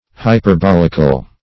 Hyperbolic \Hy`per*bol"ic\, Hyperbolical \Hy`per*bol"ic*al\, a.
hyperbolical.mp3